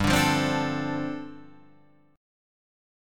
G9sus4 chord